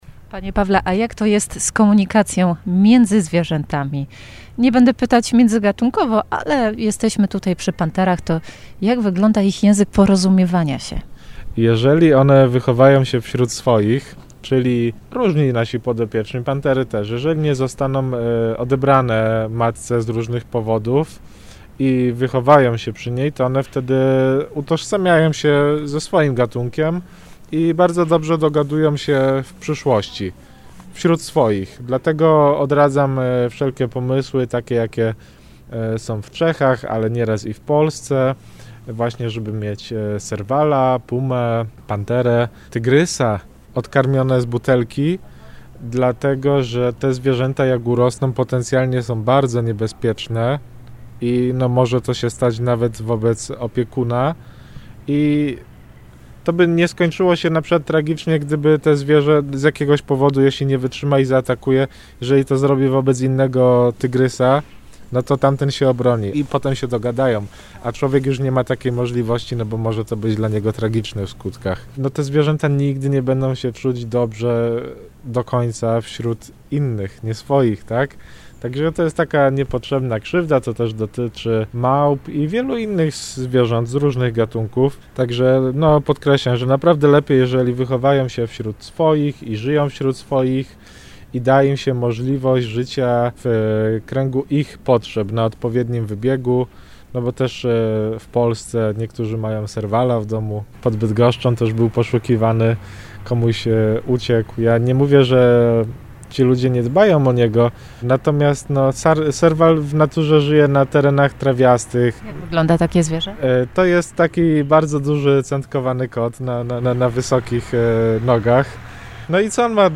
Sprawdzimy to w ZOO Ogrodzie Zoologicznym we Wrocławiu.